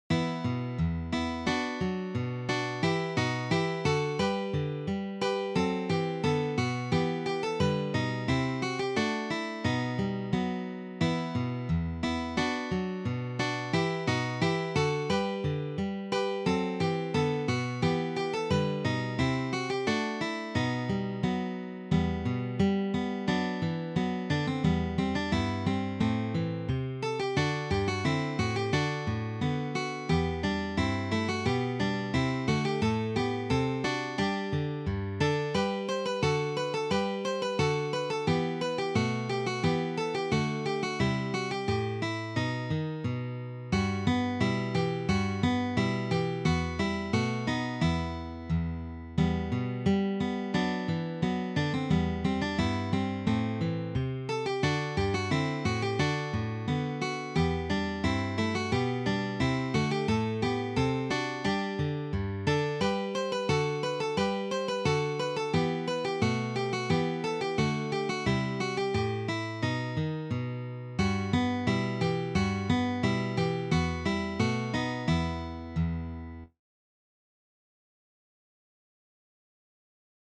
This Baroque selection is arranged for guitar trio.